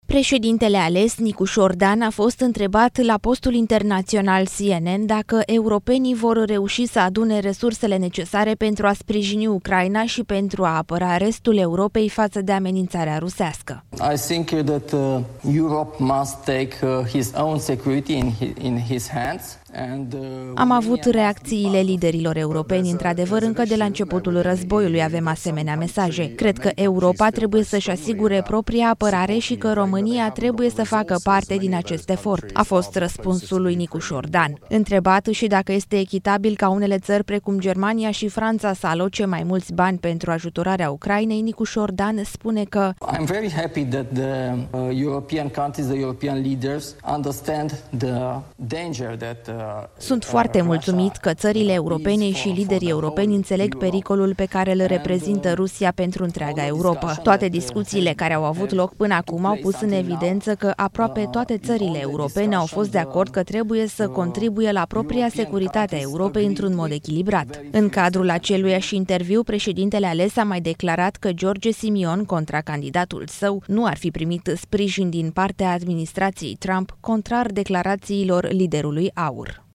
Nicușor Dan, într-un interviu pentru CNN: „Sunt foarte mulțumit că țările europene înțeleg pericolul pe care-l reprezintă Rusia pentru întreaga Europă” | AUDIO